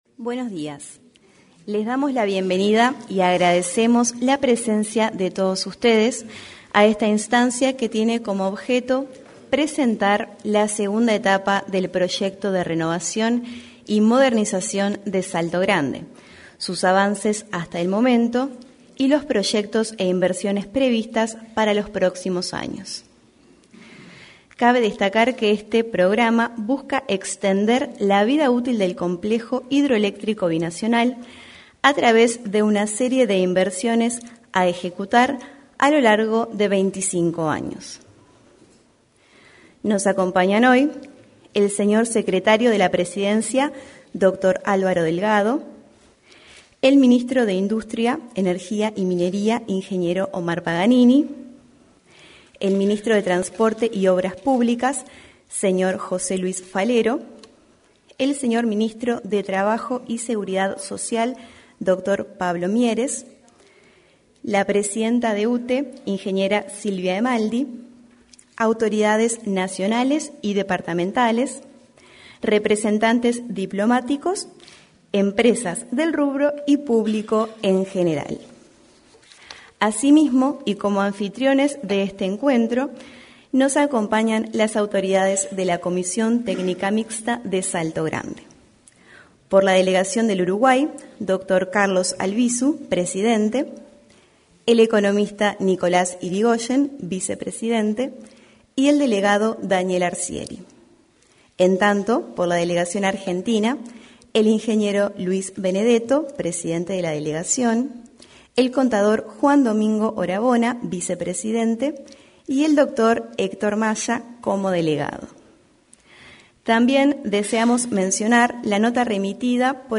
Este jueves 20, se realizó la presentación de la segunda etapa del proyecto de renovación y modernización del complejo hidroeléctrico de Salto Grande.
En la oportunidad, se expresaron el presidente de la Delegación de Argentina ante la Comisión Técnica Mixta de Salto Grande, Luis Benedetto; el vicepresidente de la de Uruguay, Nicolás Irigoyen; la titular de la UTE, Silvia Emaldi; su par de la Delegación de Uruguay ante la referida comisión, Dr. Carlos Albisu; el ministro de Industria, Energía y Minería, Omar Paganini, y el secretario de la Presidencia, Álvaro Delgado.